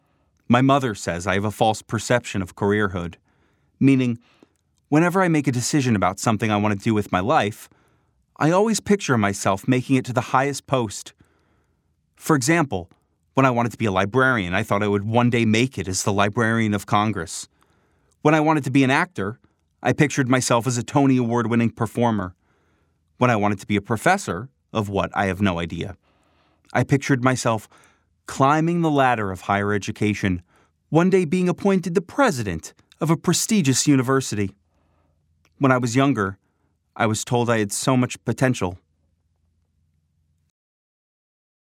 Unlike a traditional audiobook, TURN utilizes the "one-man audio drama" format to create a claustrophobic, immersive psychological landscape.